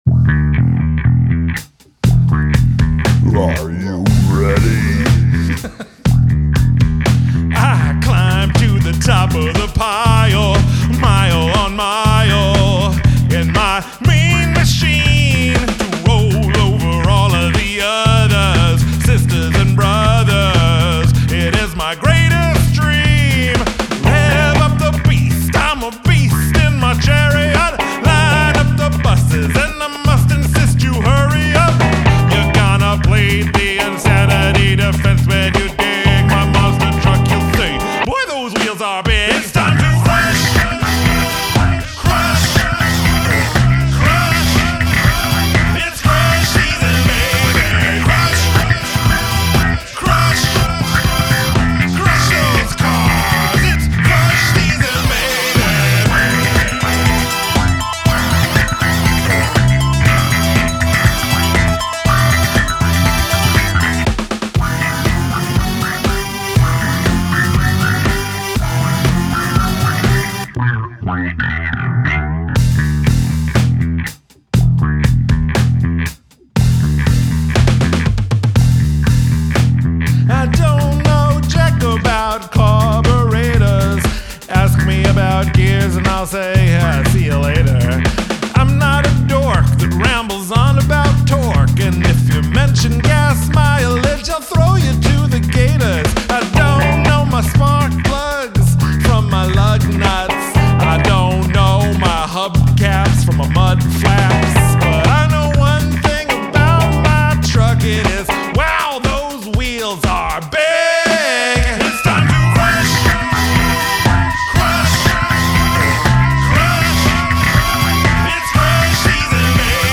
great bass. The chorus kicks it up a notch like it should.